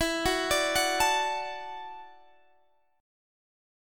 E7sus2sus4 chord